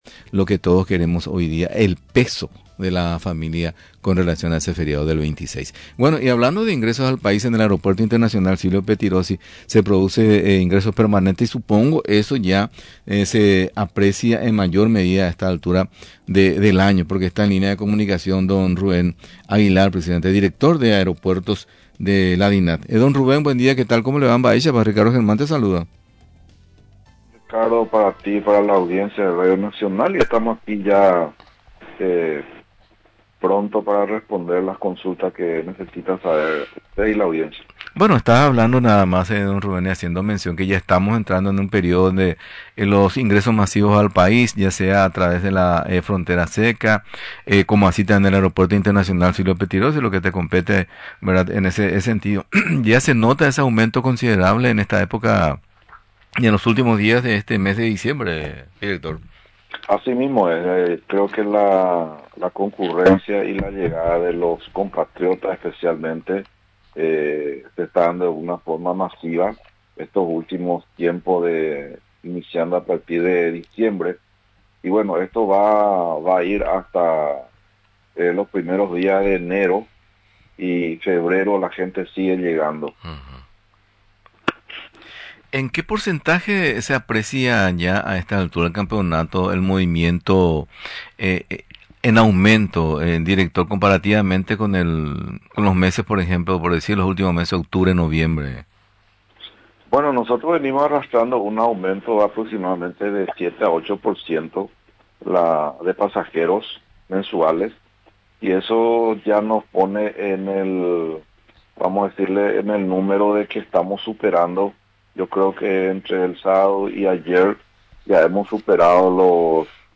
Durante la entrevista en el programa Duplex Nacional, refirió que la llegada de compatriotas se desarrolló, principalmente este fin de semana.